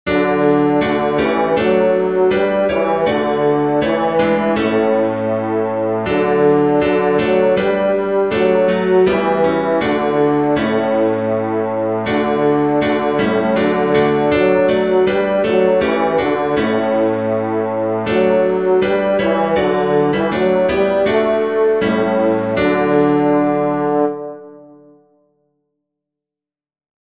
Bass
duke_street_i_know_that_my_redeemer_lives-bass.mp3